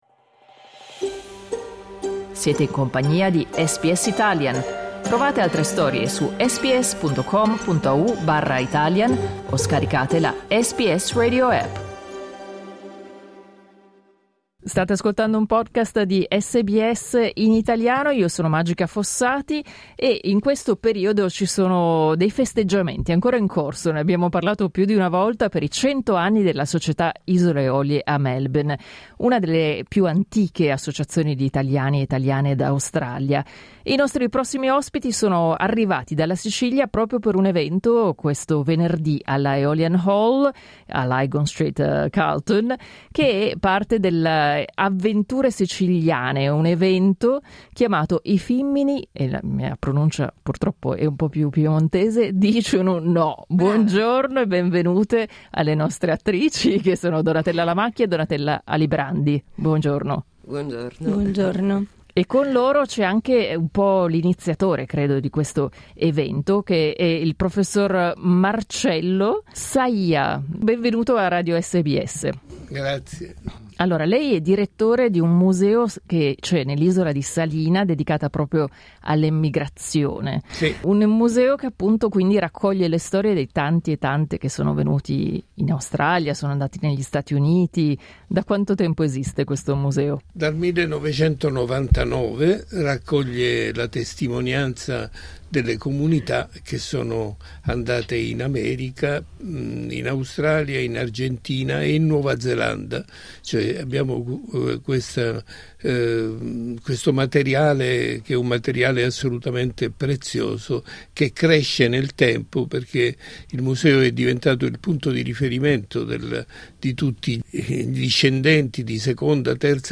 negli studi di SBS